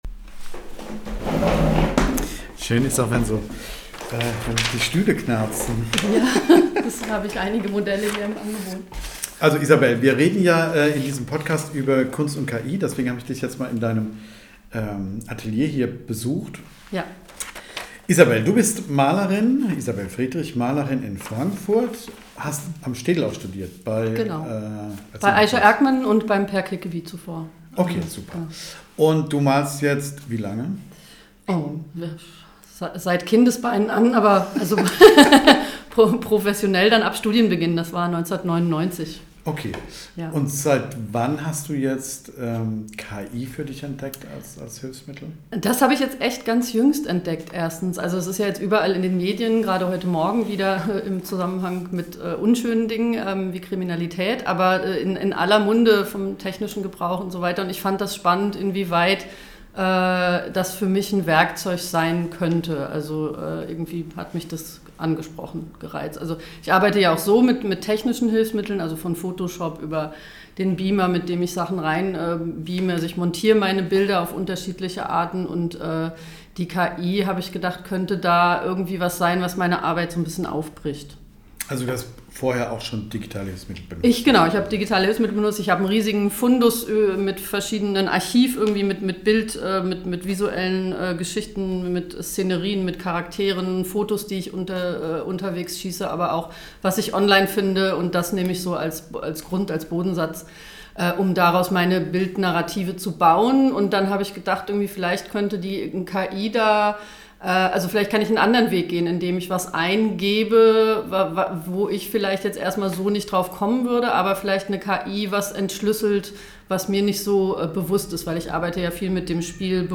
Kunst und KI – im Studio